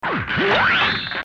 Download Free Gundam Sound Effects